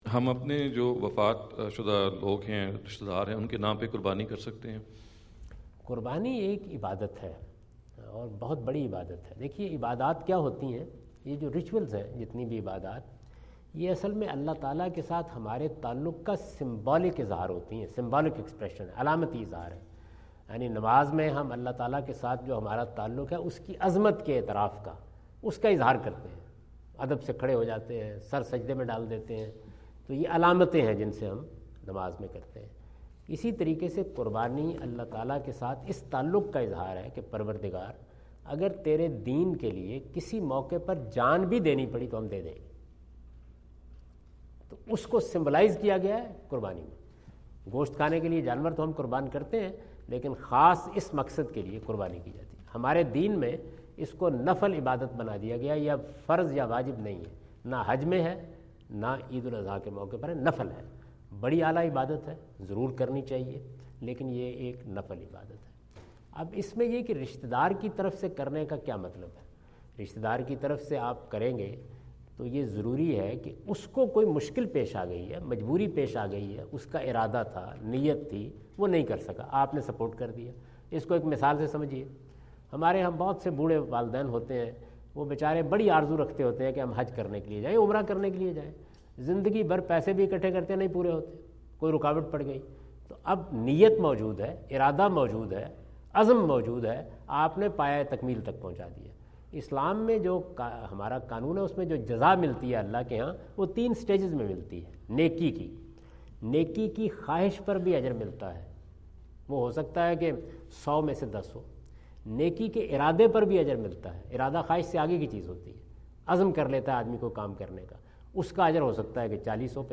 Category: English Subtitled / Questions_Answers /
Javed Ahmad Ghamidi answer the question about "Deeds of Piety on Behalf of Deceased Relatives" asked at Aapna Event Hall, Orlando, Florida on October 14, 2017.
جاوید احمد غامدی اپنے دورہ امریکہ 2017 کے دوران آرلینڈو (فلوریڈا) میں "وفات پا چکے رشتہ داروں کی طرف سے صدقہ" سے متعلق ایک سوال کا جواب دے رہے ہیں۔